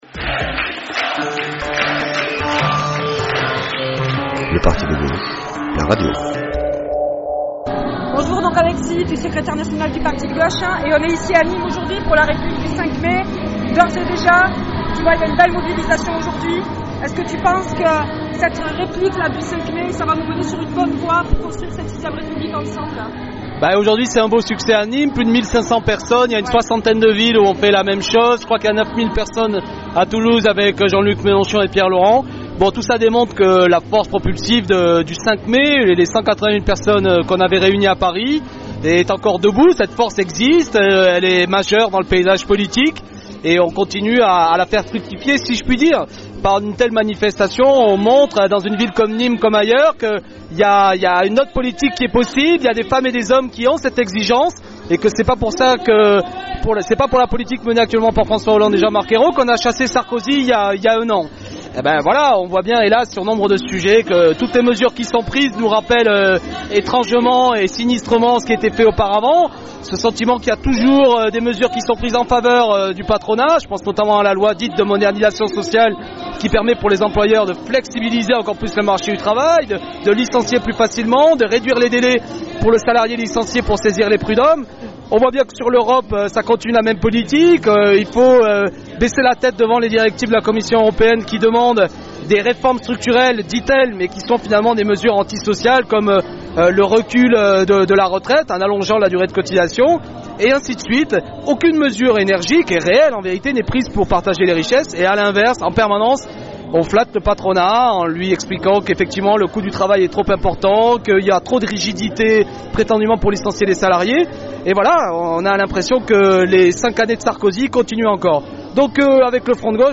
Samedi 1er Juin 2013 à Nîmes, se déroulait une des 50 répliques de la Marche de la Bastille du 05 Mai 2013.
Alexis Corbière était sur place avec les citoyens gardois, il explique, pour la radio PG, comment avancer vers la construction de cette 6ème République, et pourquoi la souveraineté populaire est la clef du projet politique d’une gauche véritable.